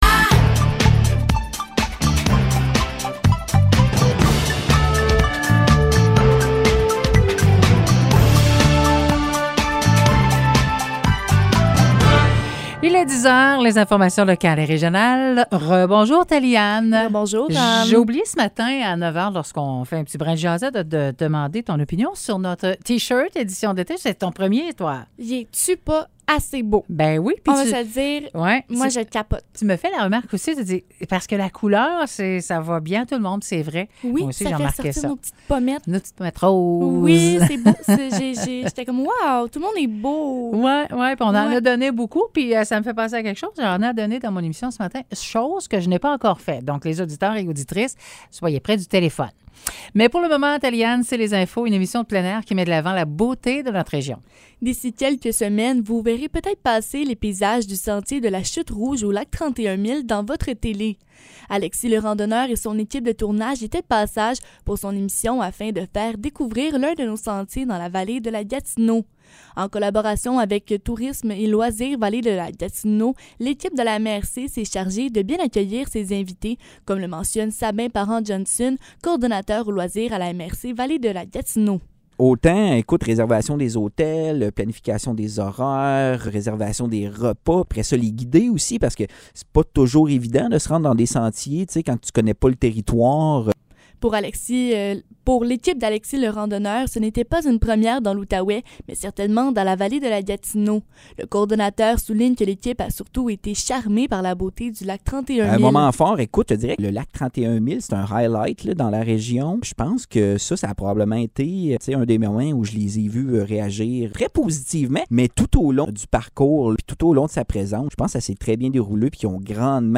Nouvelles locales - 13 juin 2022 - 10 h